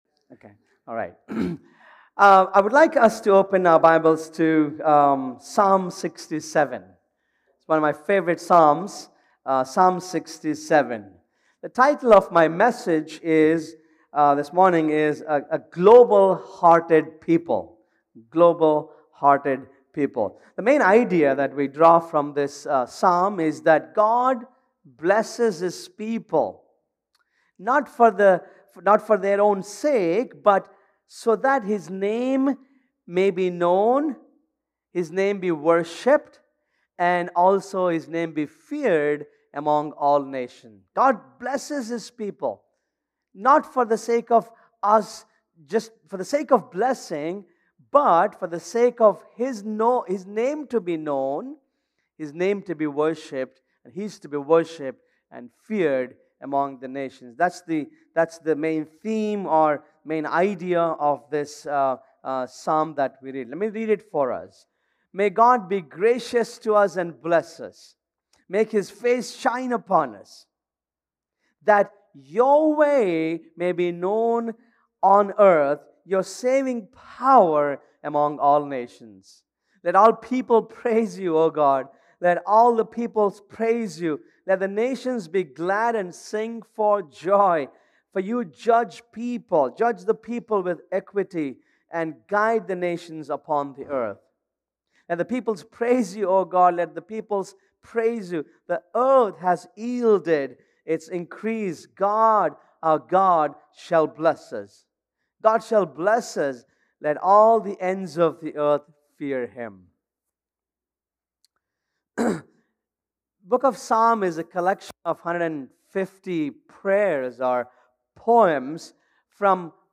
Sermon Archive
Sermons from Gateway Bible Church: Castro Valley, CA